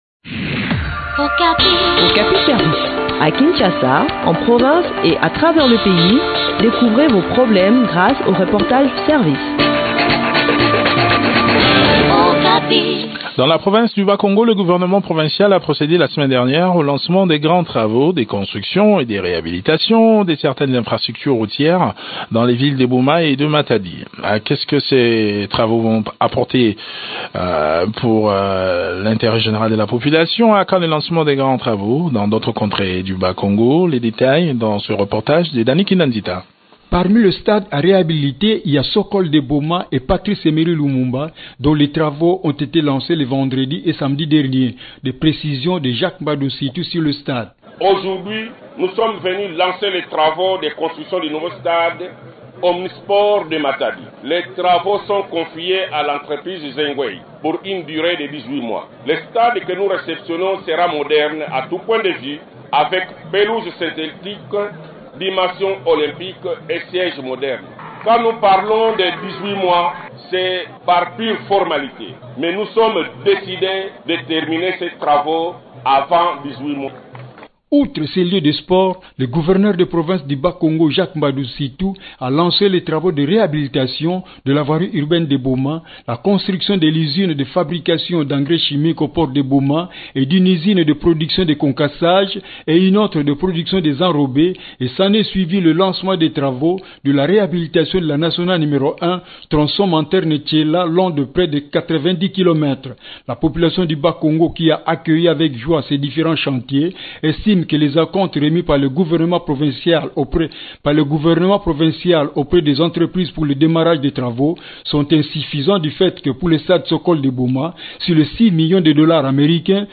Le point sur les travaux qui seront réalisés dans cet entretien